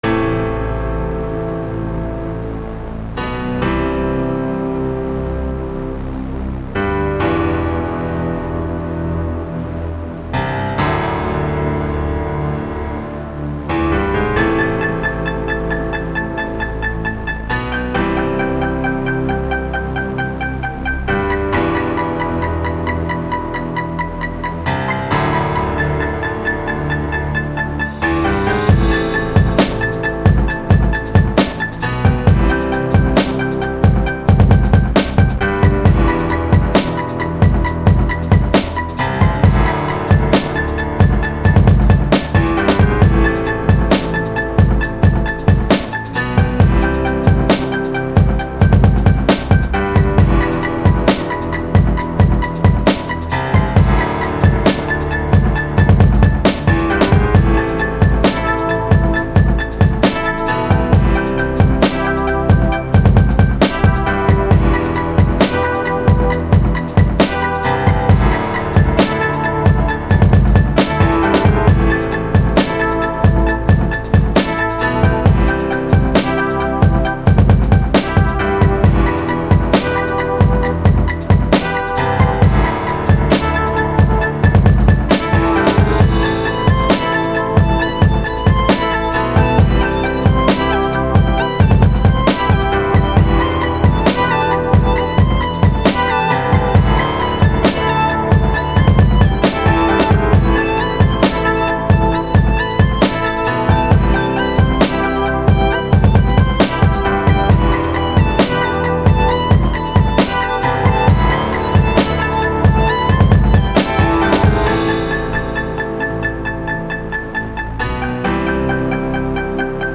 2 Thumb Up 미디어 듣기 안녕하세요 처음으로 곡 올려보네요 용량 문제로 곡 구성을 짤막하게 줄이고 음질도 192로 낮췄습니다 ㅠ 사운드클라우드는 왜 안 되는 거지..